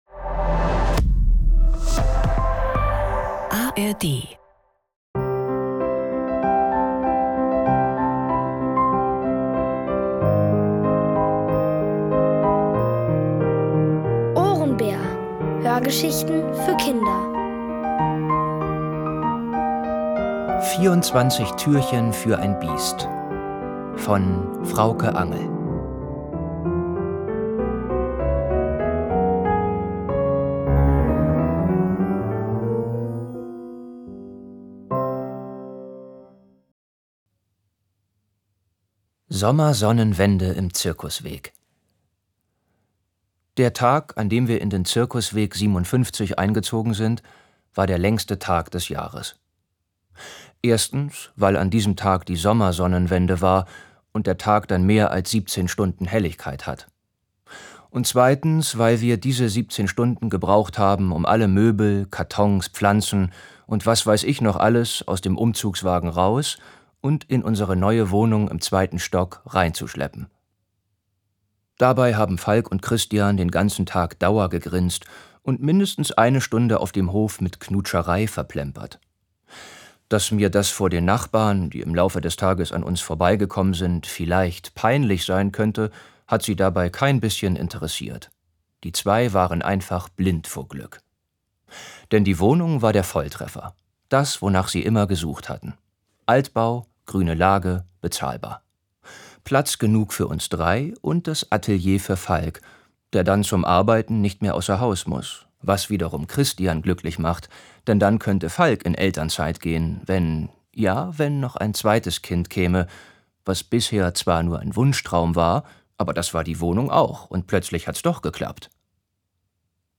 Alle 5 Folgen der OHRENBÄR-Hörgeschichte: 24 Türchen für ein Biest von Frauke Angel.